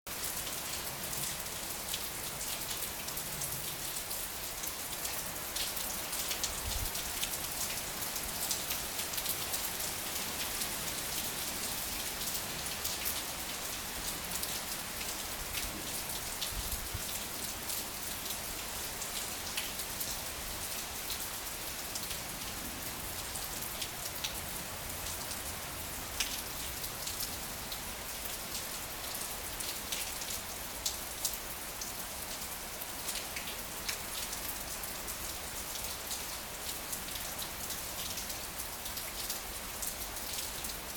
雨風の中
フィールドに出かけたは良いが、だんだんと雨と風が強くなっていった。
鳥はウグイスだけが鳴いている。
ボーと強い雨風に揺れる森を眺めていて、音を録ったら面白いかもと思って、動画で記録してみた。